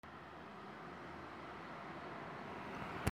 早朝 鳥
盛岡 D50